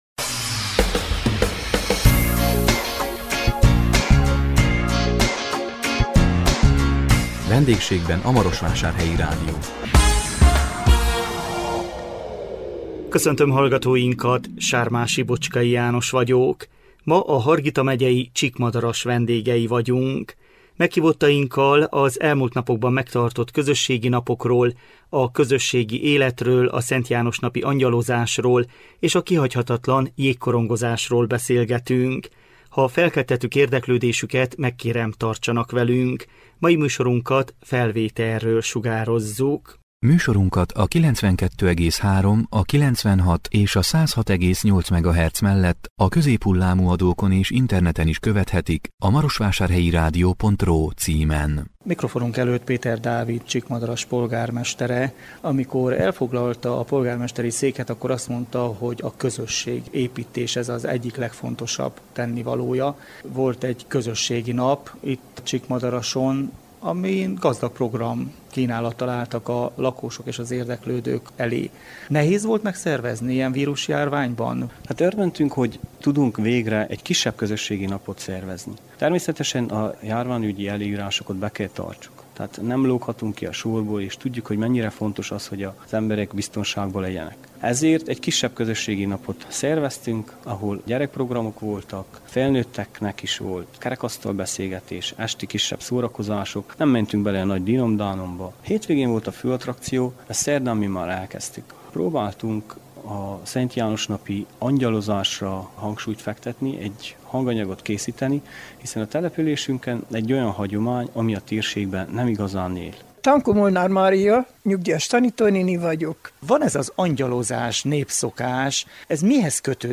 A 2021 július 1-én jelentkező VENDÉGSÉGBEN A MAROSVÁSÁRHELYI RÁDIÓ című műsorunkban a Hargita megyei Csíkmadaras vendégei voltunk. Meghívottainkkal az elmúlt napokban megtartott közösségi napokról, a közösségi életről, a Szent János napi angyalozásról és a kihagyhatatlan jégkorongozásról beszélgettünk.